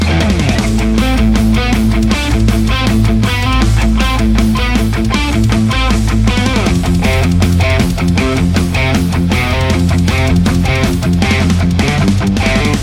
Metal Riff Mix
RAW AUDIO CLIPS ONLY, NO POST-PROCESSING EFFECTS